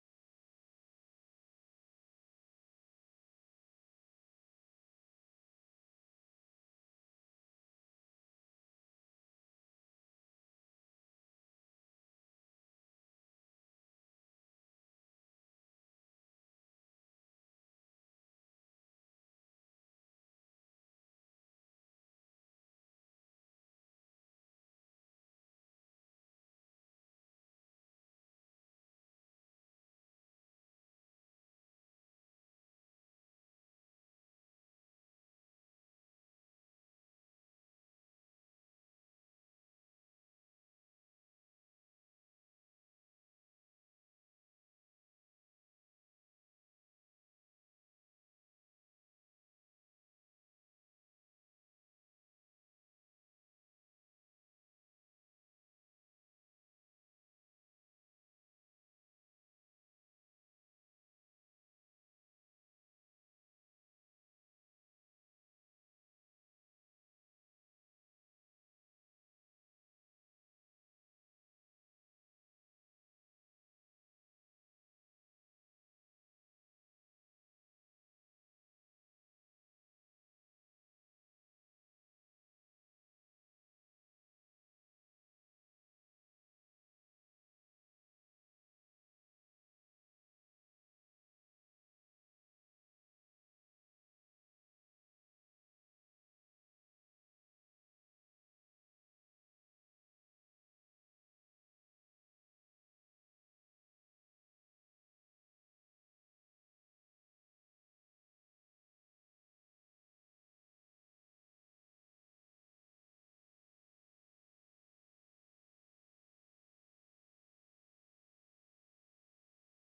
Being a Christian – A Fig Tree Does Not Bear Olives Sermon
Being-a-Christian-A-Fig-Tree-Does-Not-Bear-Olives-Sermon-Audio--CD.mp3